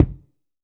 B.B KICK11.wav